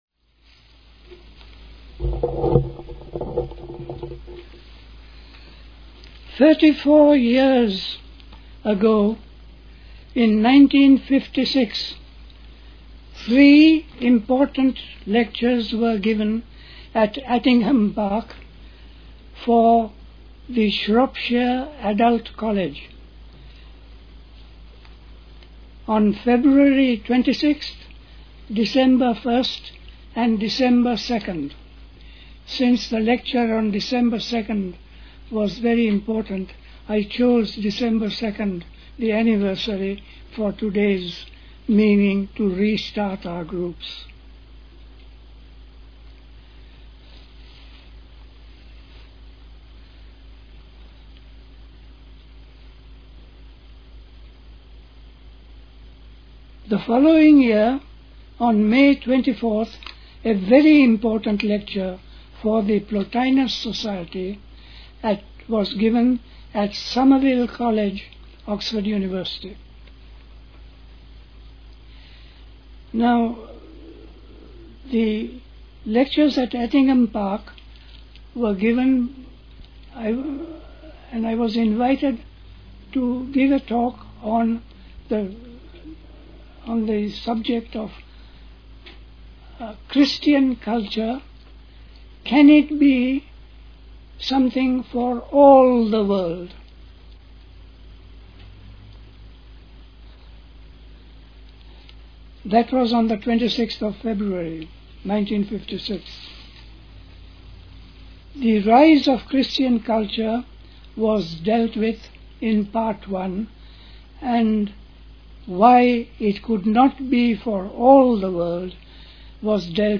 A talk
at Dilkusha, Forest Hill, London